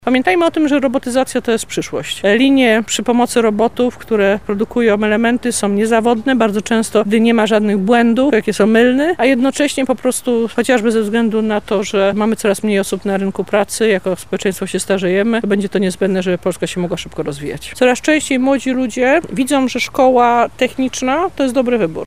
– mówi Katarzyna Lubnauer, wiceminister edukacji narodowej.